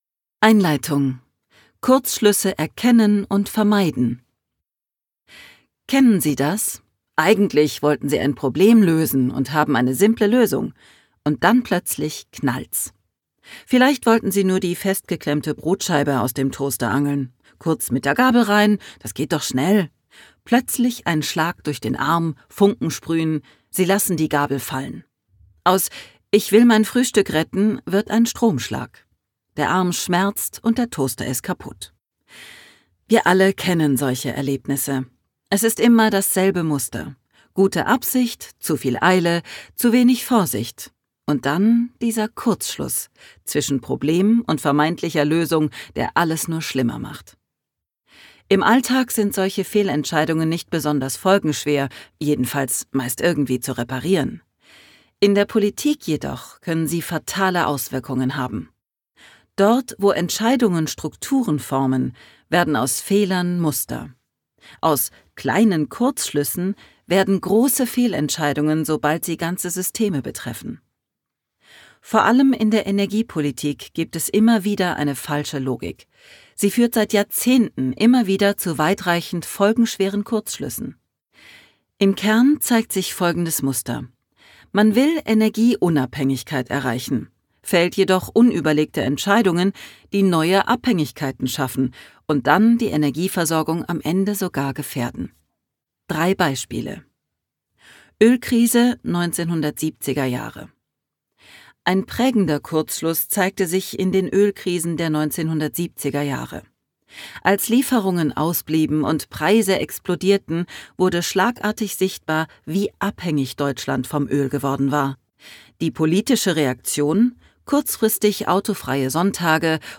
Ein Hörbuch für alle, die sich der Illusion verweigern, dass es noch einfache Antworten gibt.
Gekürzt Autorisierte, d.h. von Autor:innen und / oder Verlagen freigegebene, bearbeitete Fassung.